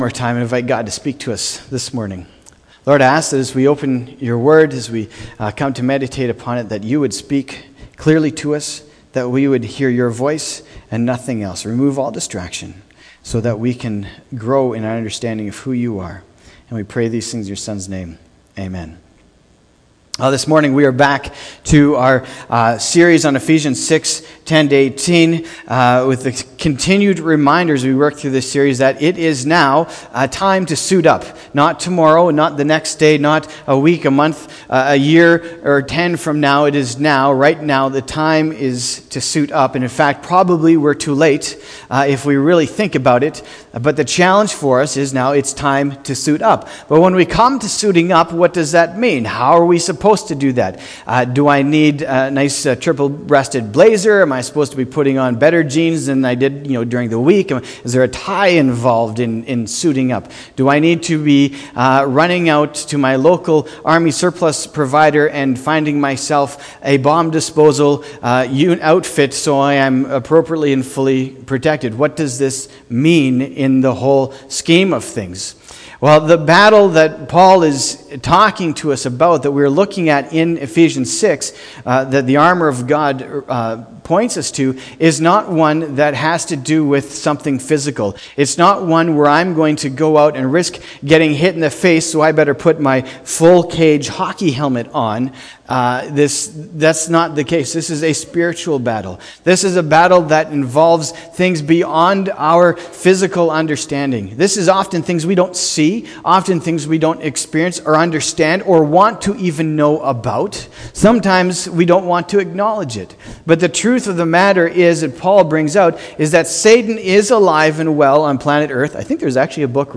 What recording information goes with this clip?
Argyle Road Baptist Church